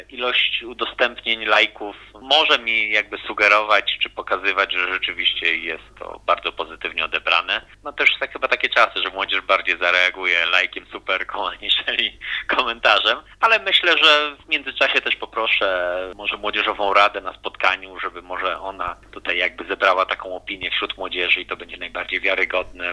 – Mówi Grzegorz Kulbicki, Burmistrz Dębna.